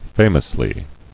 [fa·mous·ly]